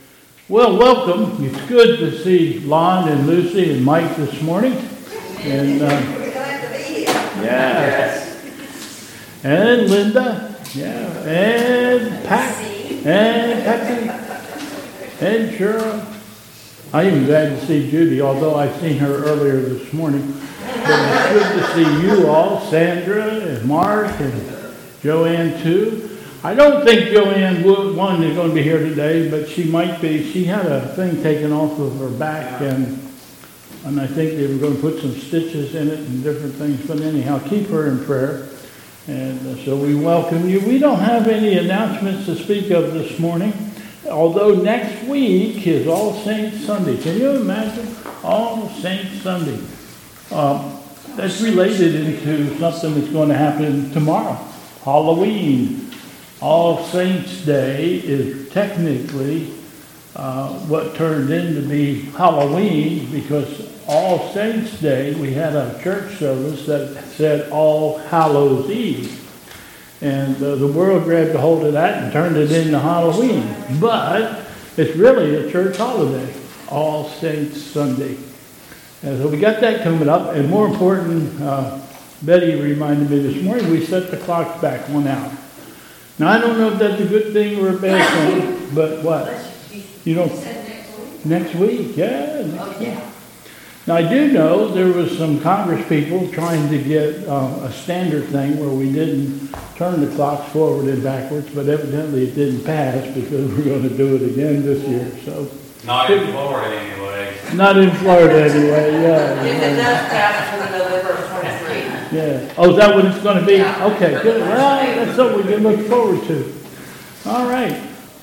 A Service of Favorite Hymns
Prelude: "Sing Alleluia to the Lord